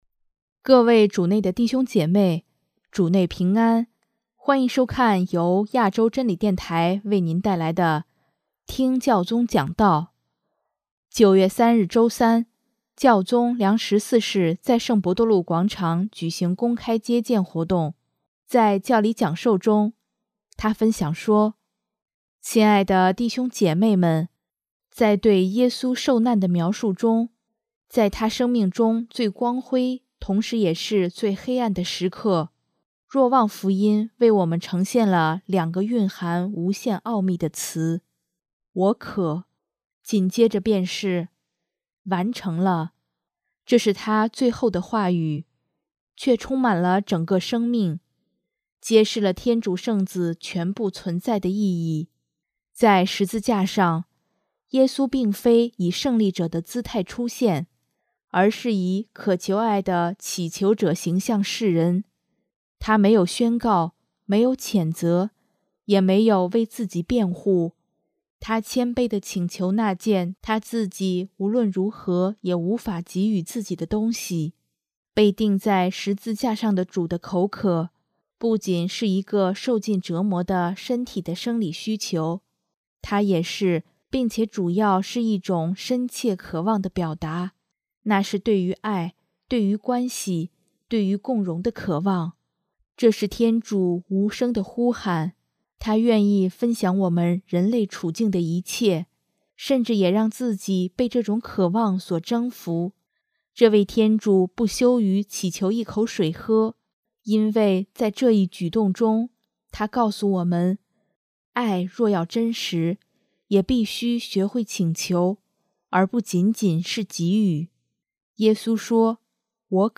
【听教宗讲道】|爱若要真实，也必须学会请求，而不仅仅是给予
9月3日周三，教宗良十四世在圣伯多禄广场举行公开接见活动。